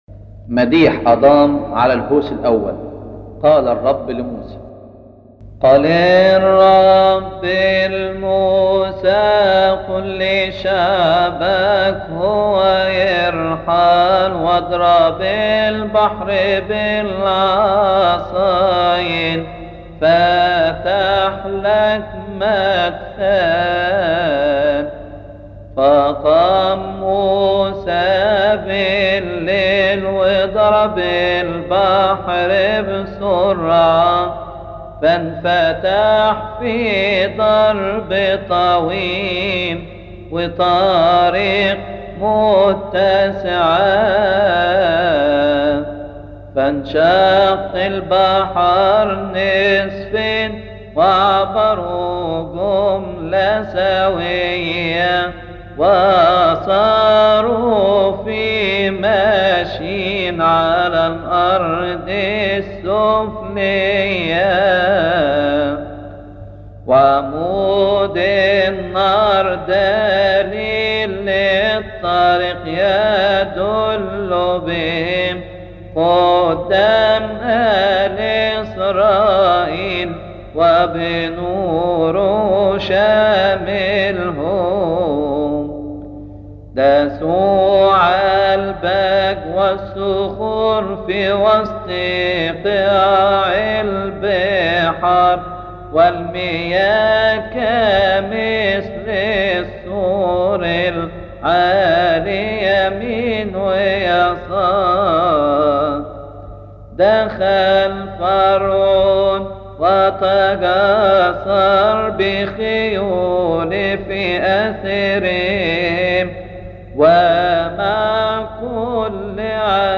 مديح يقال بعد لبش الهوس الأول - مكتبة الألحان - كنيسة الشهيد العظيم مارجرجس بنزلة السمان
يقال بعد لبش الهوس الاول في تسبحة نصف الليل بشهر كيهك